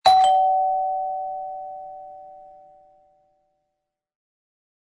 • God lyd
Mekanisk ringeklokke i lekkert design fra Honeywell.
Honeywell D230 – Big Ben / Mekanisk dørklokke (kablet)